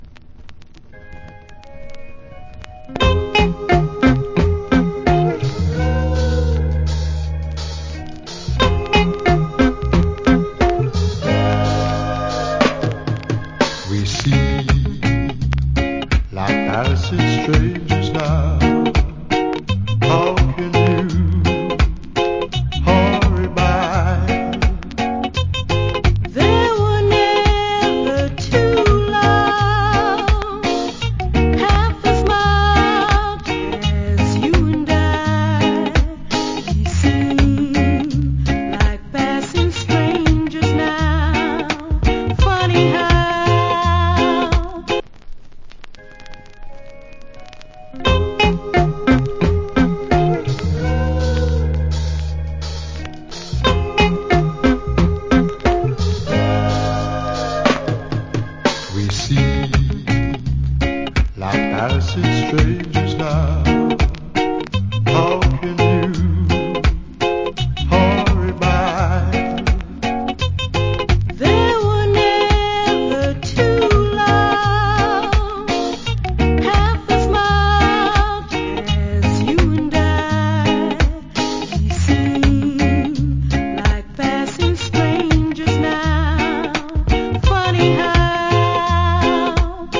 Nice Duet Reggae Vocal.